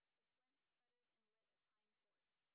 sp29_white_snr10.wav